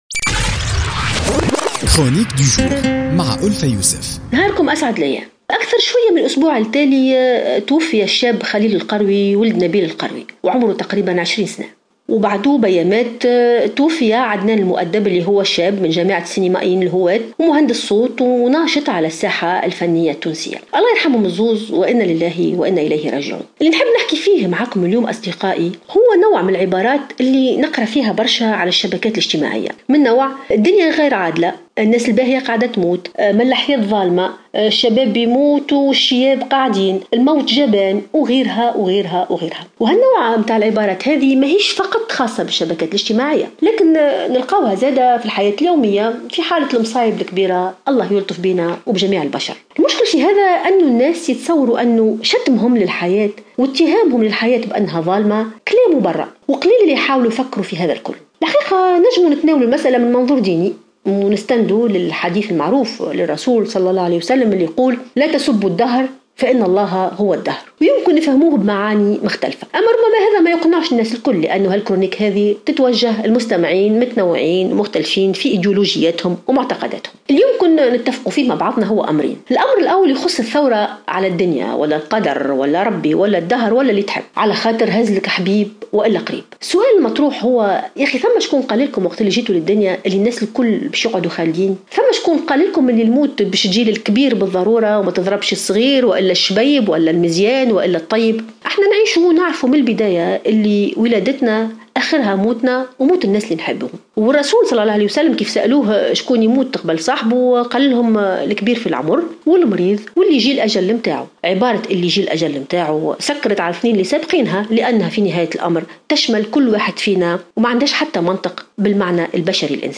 انتقدت الكاتبة ألفة يوسف في افتتاحيتها لليوم الخميس على "الجوهرة أف أم" سلوكيات الذين يشتمون الدهر عندما تحل بهم مصيبة الموت.